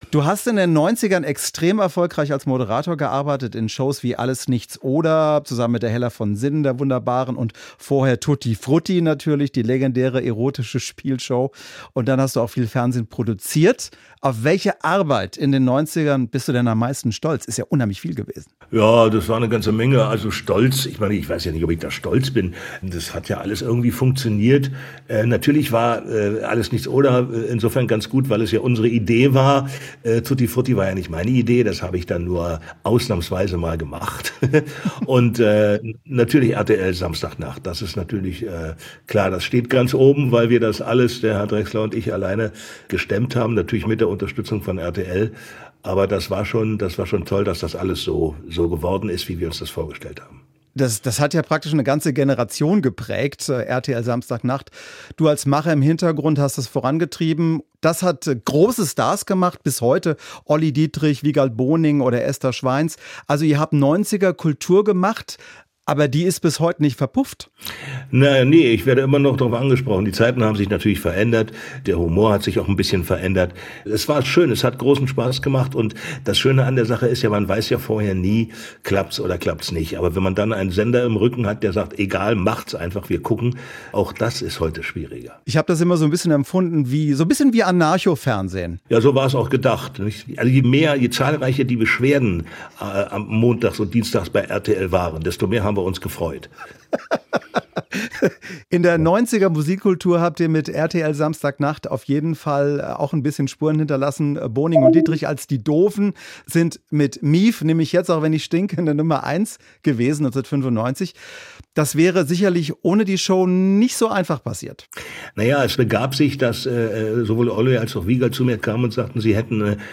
SWR1 Interviews
Interview mit